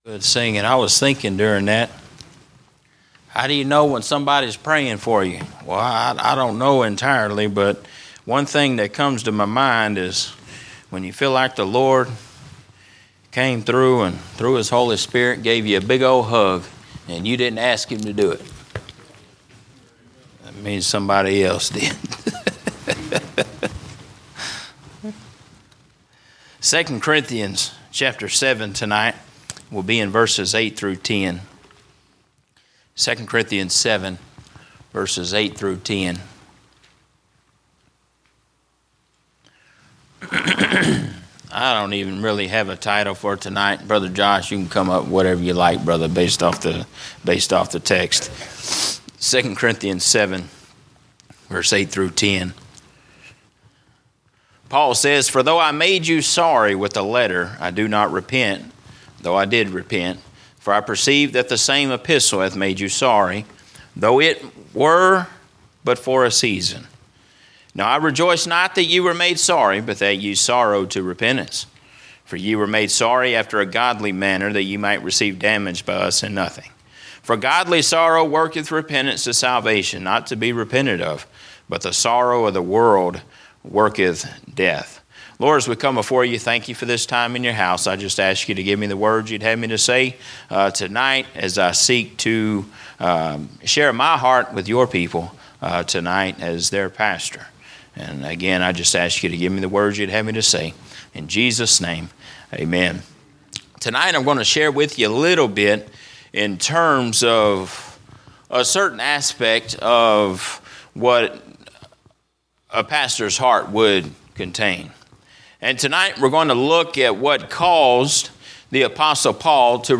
From Series: "General Preaching"